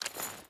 Footsteps / Water
Water Chain Walk 2.wav